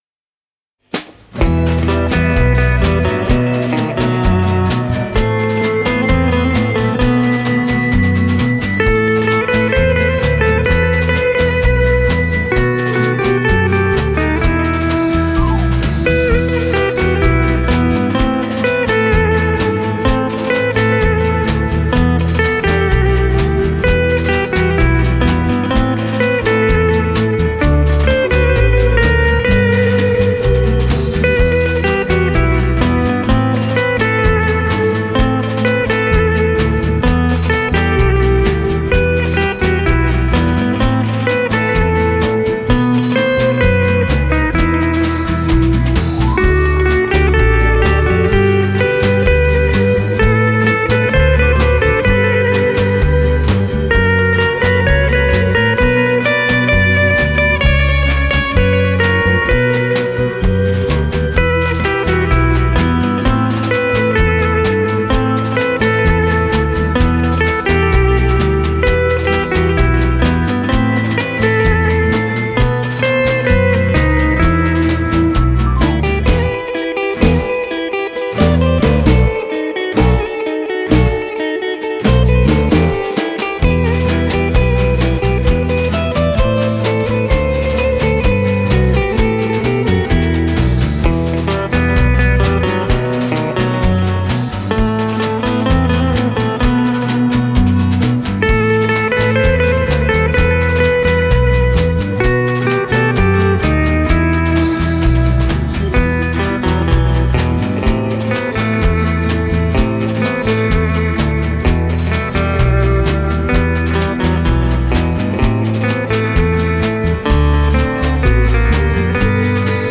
Это обработка танго